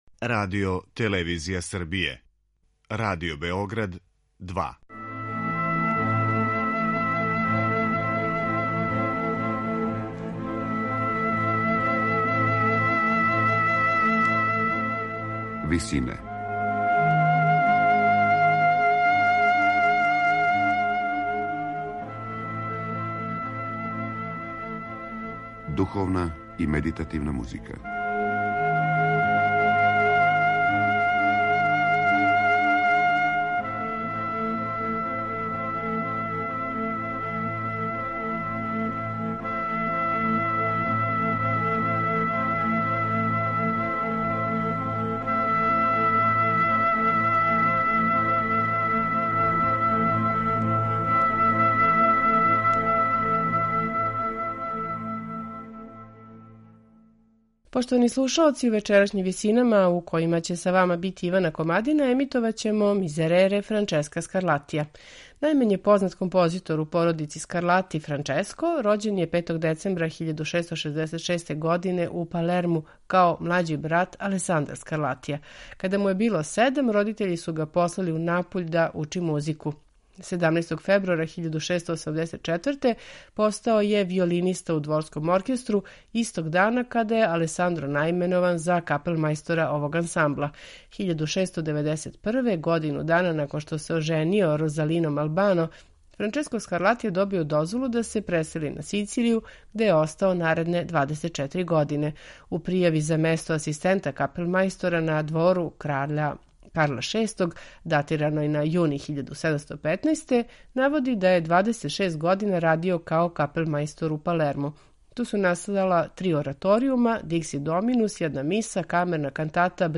Као и друга сачувана Скарлатијева дела тог жанра, и Miserere је писан у монументалној римској традицији, са великим композиционим умећем.